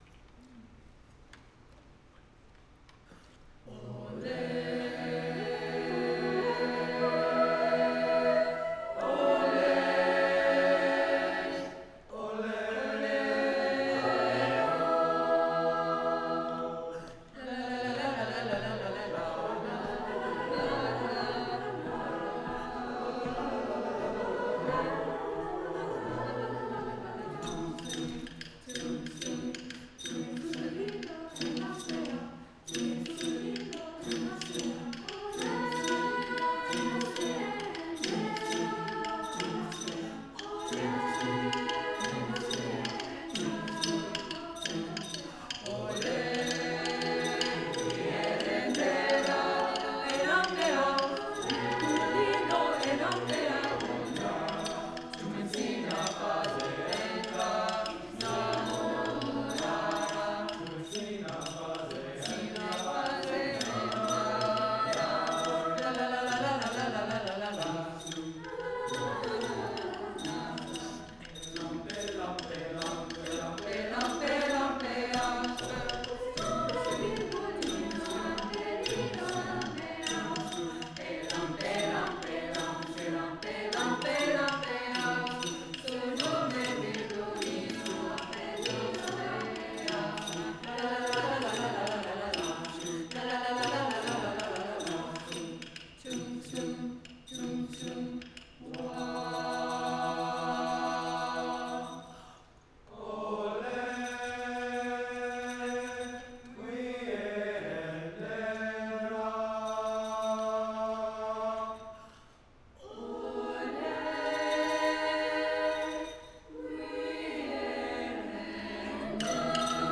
the students
Chamber, Choral & Orchestral Music
2:00 PM on August 10, 2014, St. Mary Magdalene
Chorus